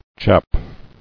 [chap]